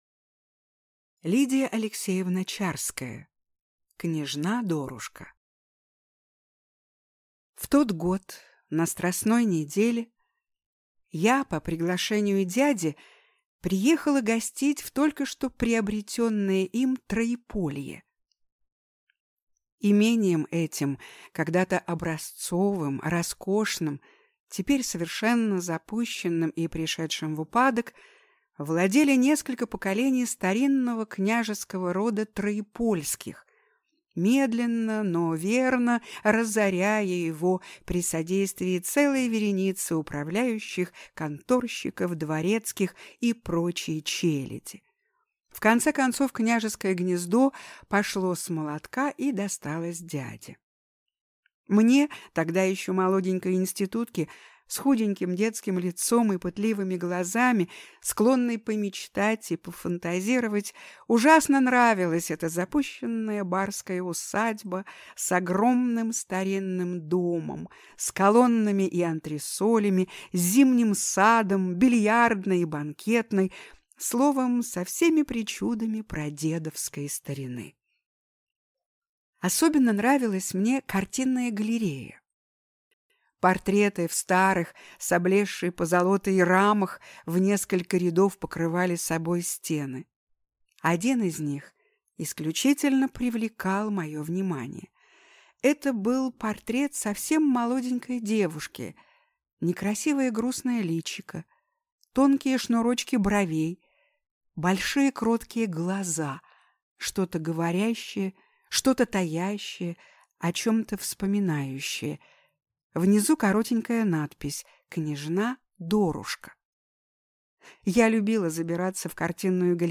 Аудиокнига Княжна Дорушка | Библиотека аудиокниг